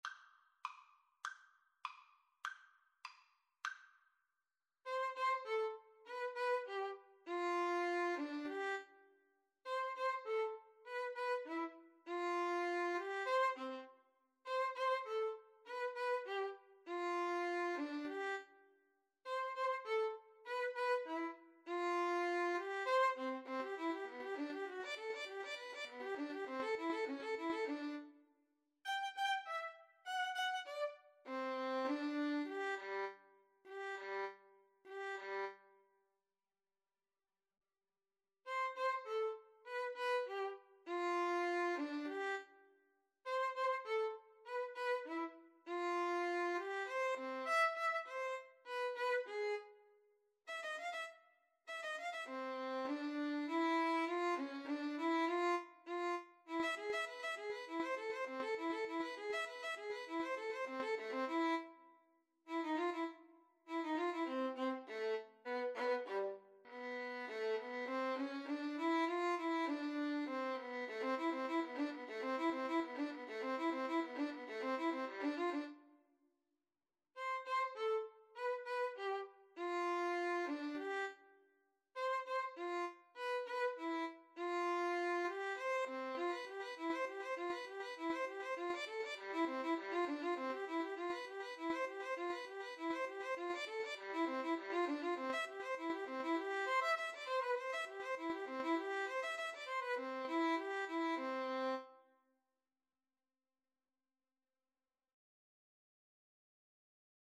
2/4 (View more 2/4 Music)
Violin Duet  (View more Advanced Violin Duet Music)
Classical (View more Classical Violin Duet Music)